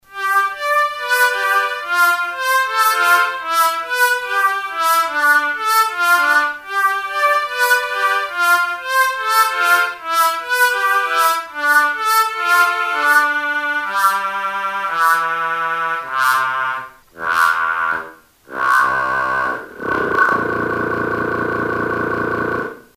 So the effect is you have three strong tones: the master frequency and the two formants. Adjusting the master's pulse width alters the ratio of the two formants.